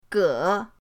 ge3.mp3